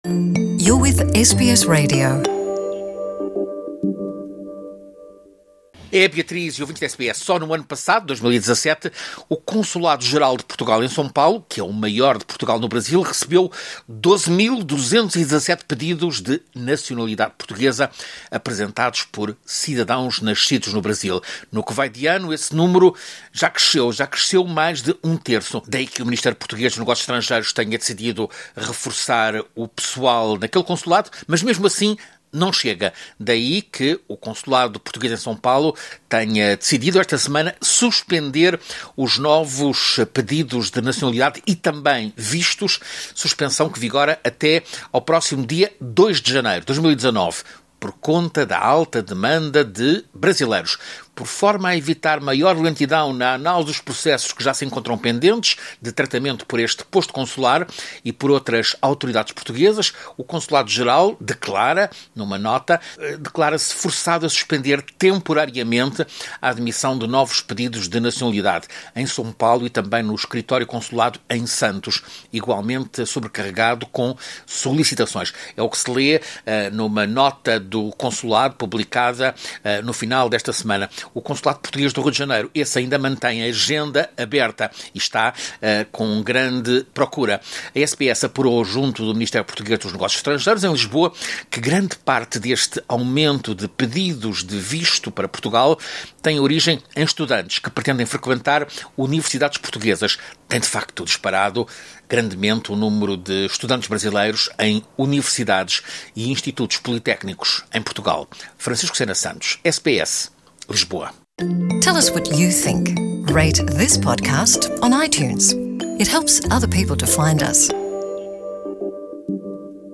Ouça reportagem do correspondente da SBS em Português